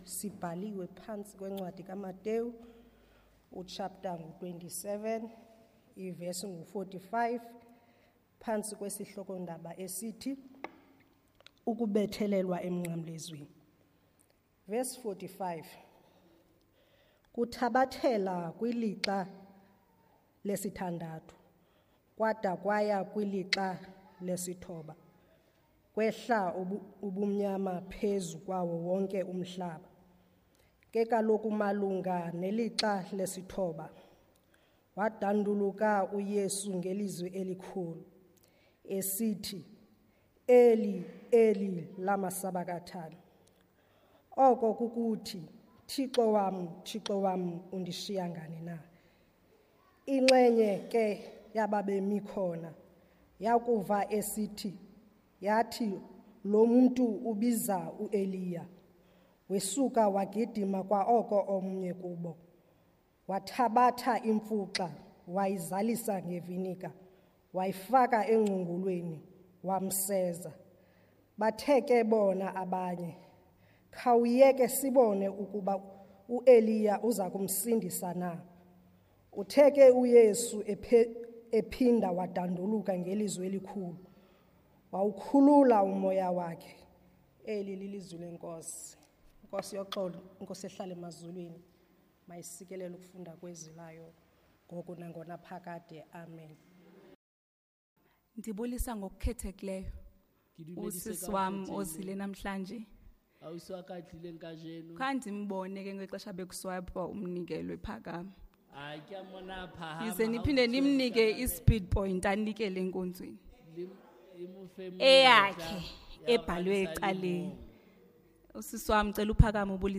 Trinity Methodist Church Sermons